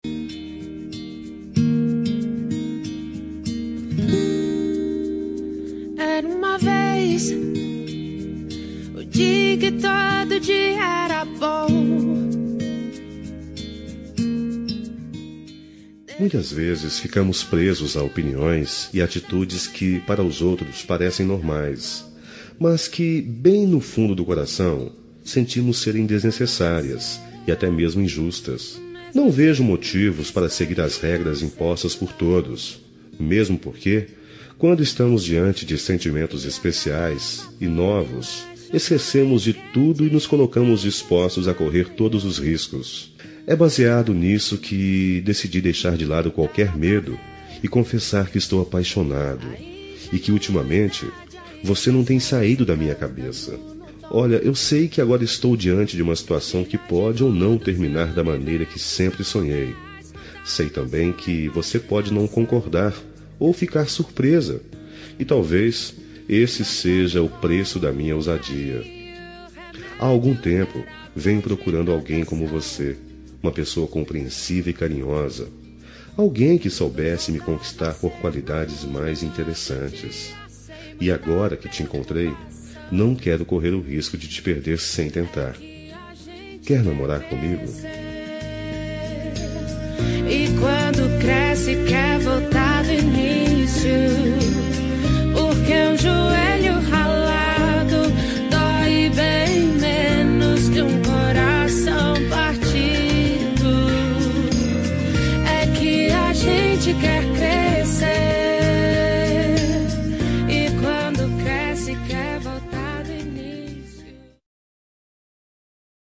Telemensagem de Pedido – Voz Masculina – Cód: 041713 – Quer Namorar